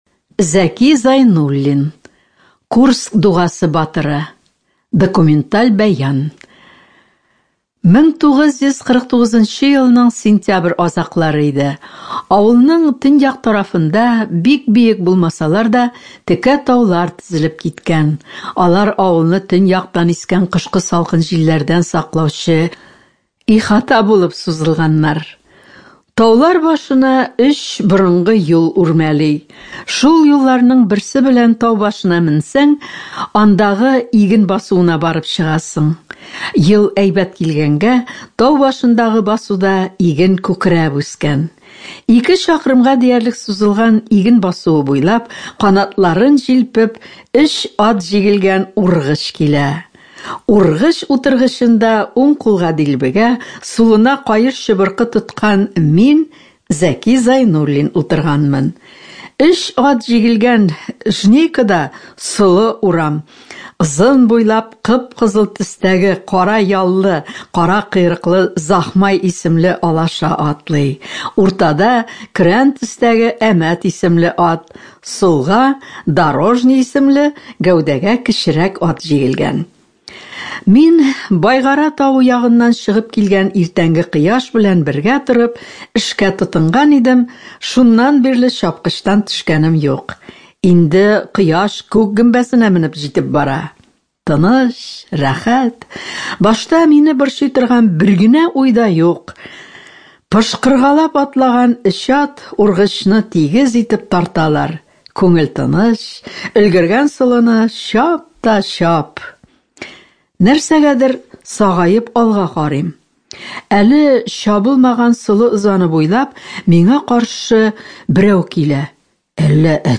Студия звукозаписиТатарская республиканская специальная библиотека для слепых и слабовидящих